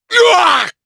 Chase-Vox_Damage_jp_03.wav